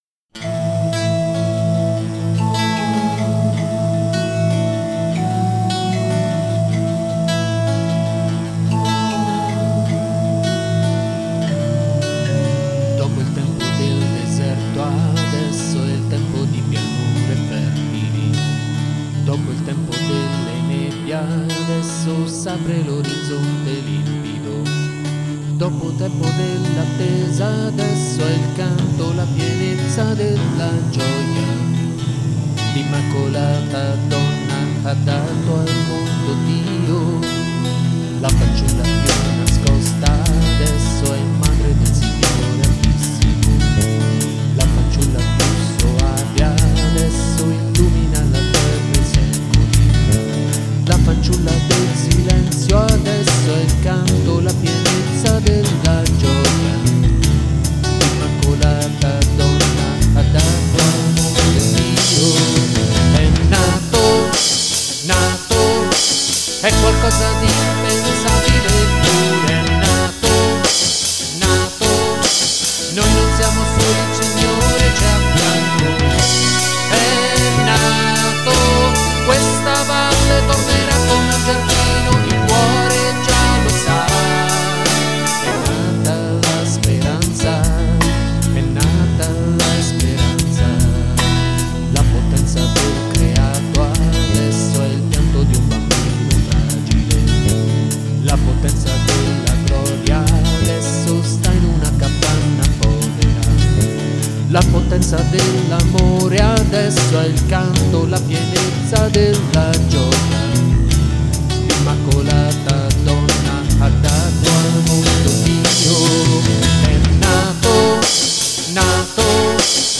Traccia Tenori